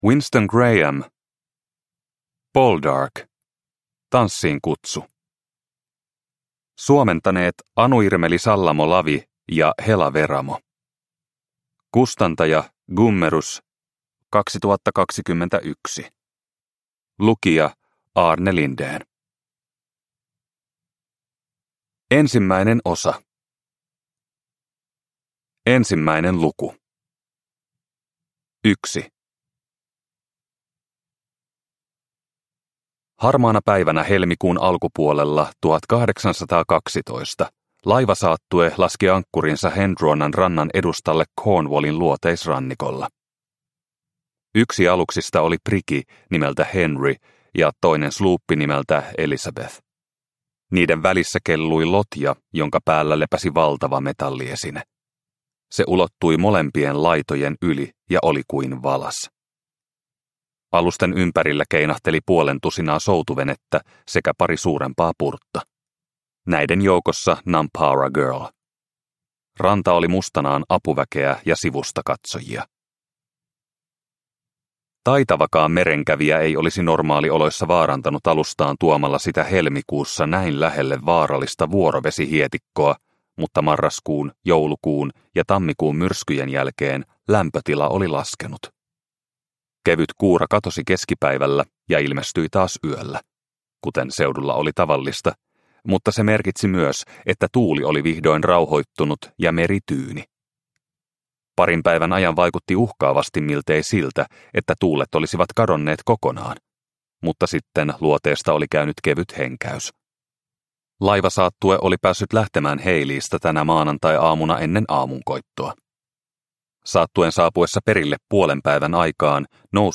Poldark - Tanssiinkutsu (ljudbok) av Winston Graham